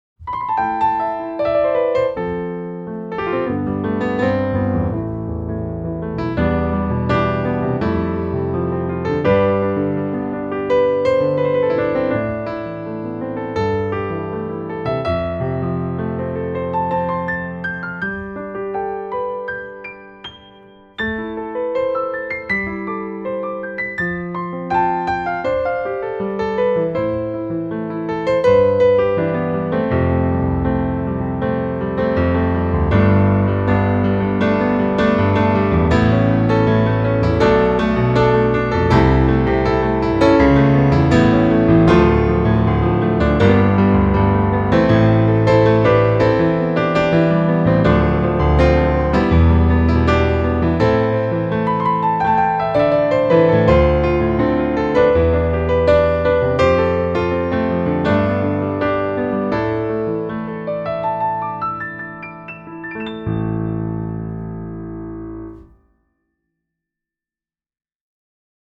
世界上最珍贵的音乐会大三角钢琴之一，声音细腻丰厚。
充满活力的现代三角钢琴
丰富、均衡，拥有现代歌声般的声音
声音类别: 音乐会大钢琴